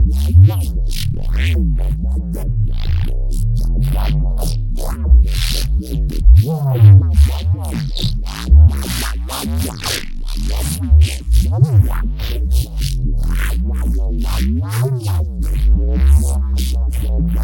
too much pitch bend.wav